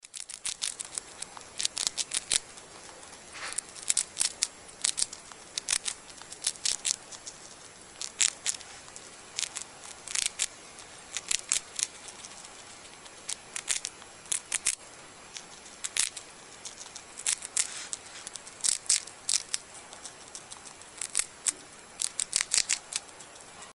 Звуки мышей
писк мышей третий вариант